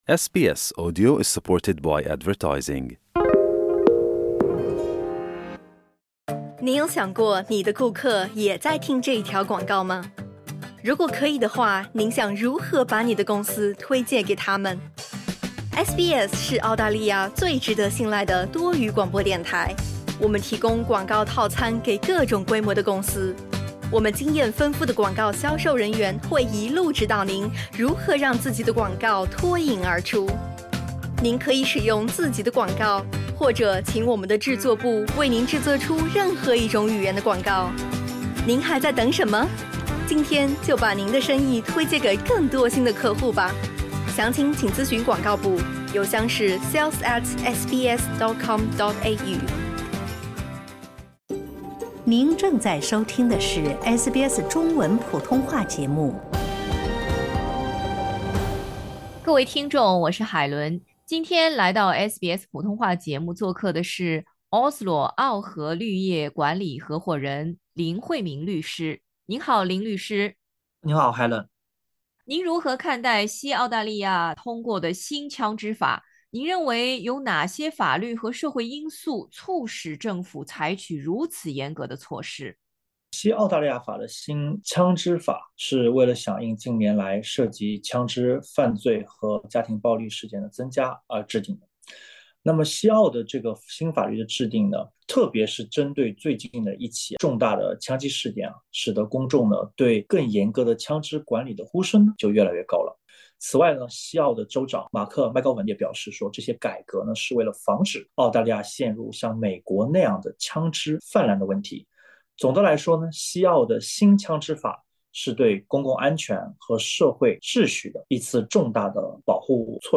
请点击音频，听专家详解。